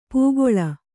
♪ pūgoḷa